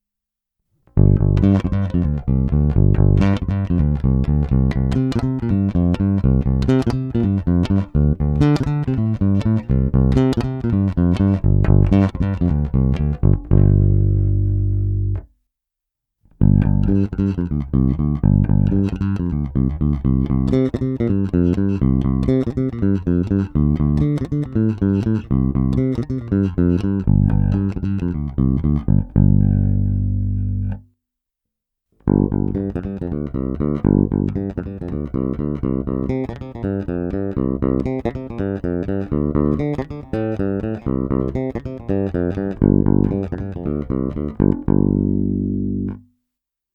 Není-li uvedeno jinak, následující nahrávky jsou provedeny rovnou do zvukovky, jen normalizovány a dále ponechány bez jakéhokoli postprocesingu. Korekce byly nastaveny na střední neutrální poloze.